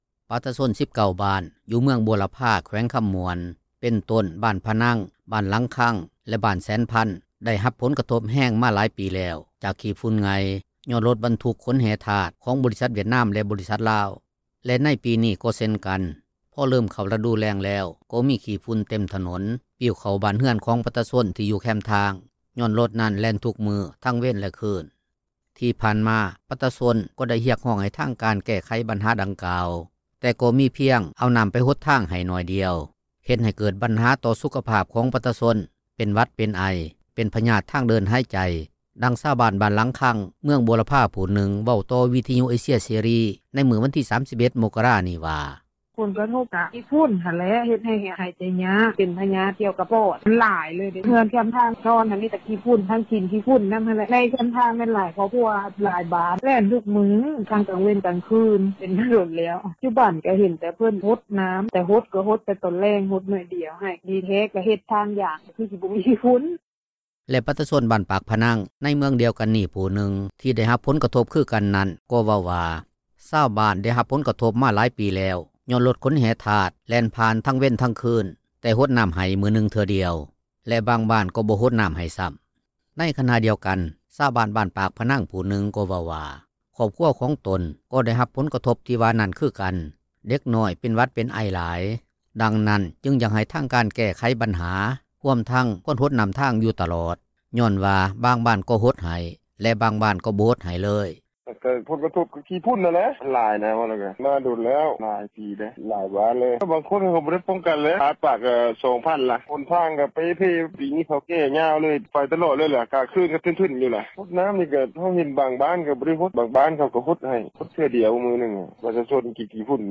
ດັ່ງຊາວບ້ານ ບ້ານຫລັງຄັງ ເມືອງບົວລະພາຜູ້ນຶ່ງ ເວົ້າຕໍ່ວິທຍຸເອເຊັຽເສຣີ ໃນມື້ວັນທີ 31 ມົກກະຣານີ້ວ່າ: